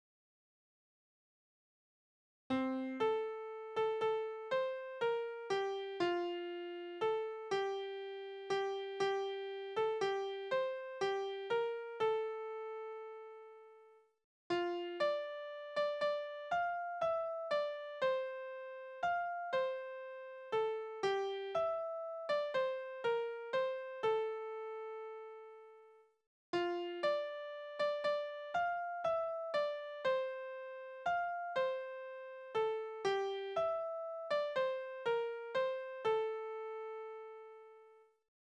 Naturlieder
Tonart: F-Dur
Taktart: 6/8, (6/4)
Tonumfang: Oktave, Quarte
Besetzung: vokal